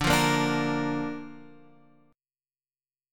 Dm#5 Chord